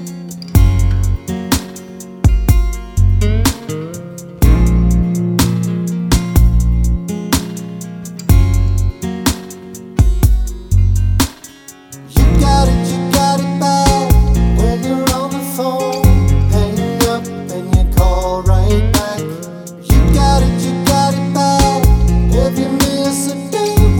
No Chorus Backing Vocals R'n'B / Hip Hop 4:14 Buy £1.50